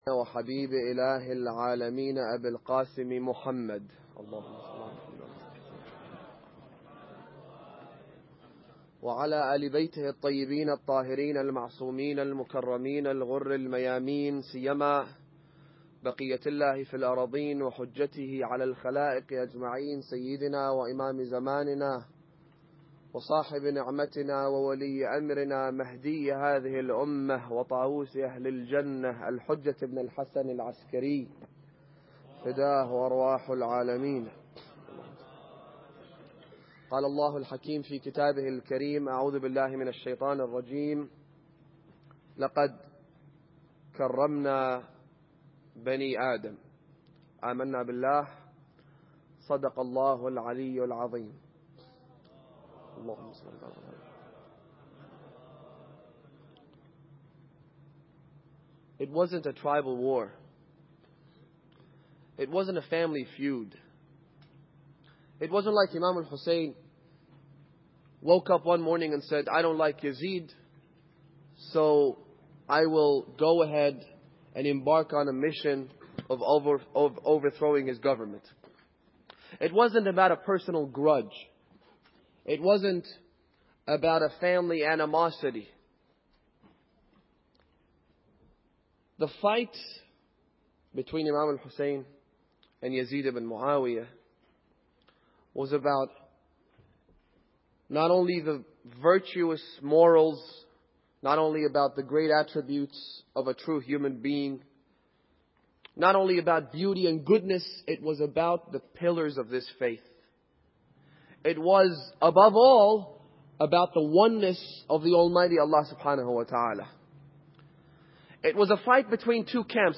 Muharram Lecture 1